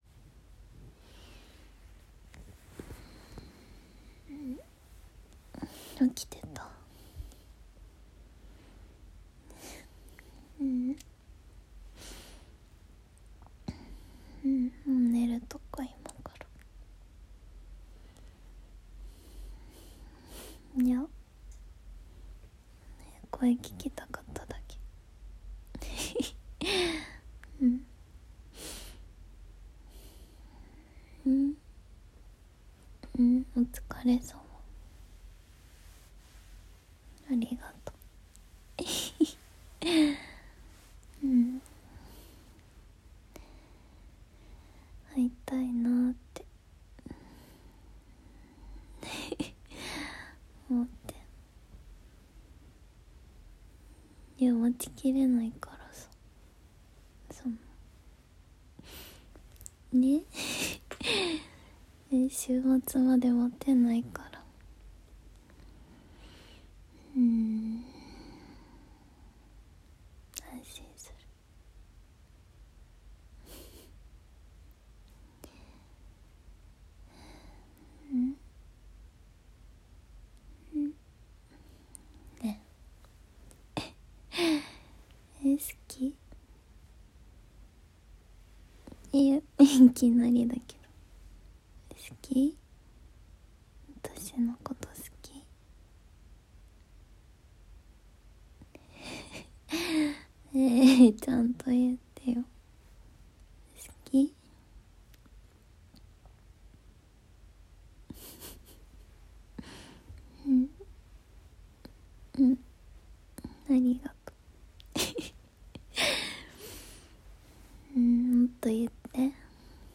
【通話】大好きな君と、寝る前におはなし